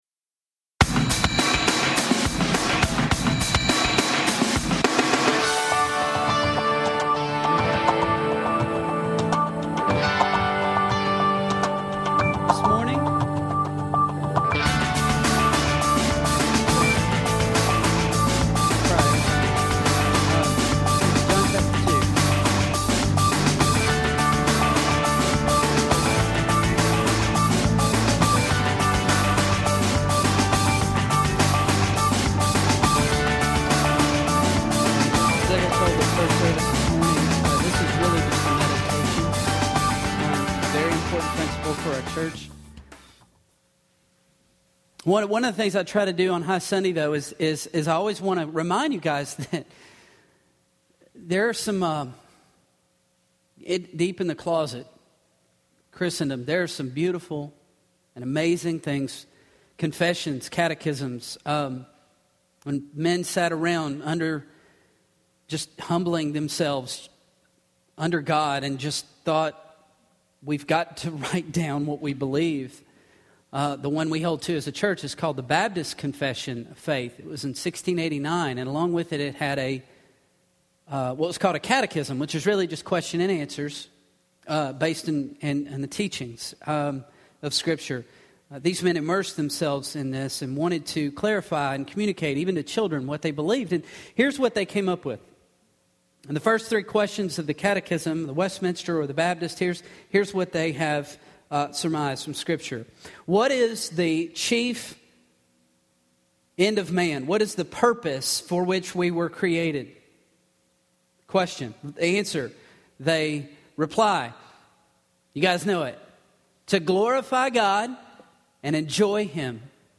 A message from the series "High Sunday."